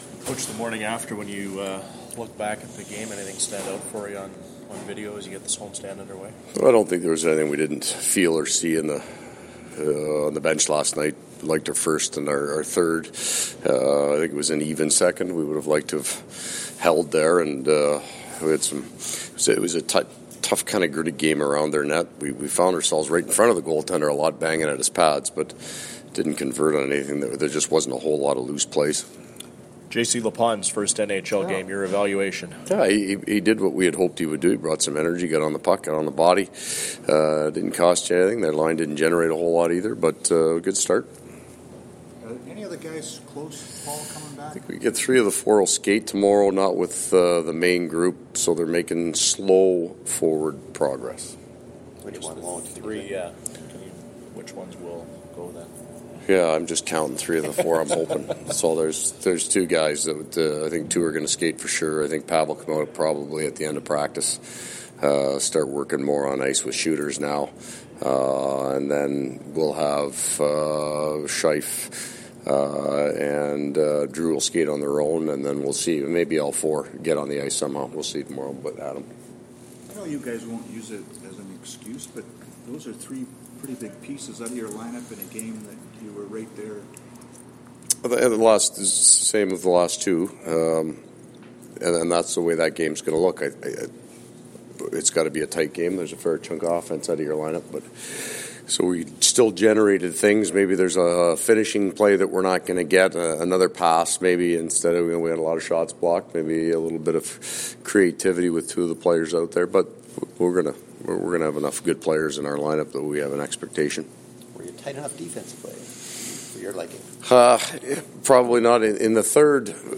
Coach scrum
Coach Maurice’s post practice comments.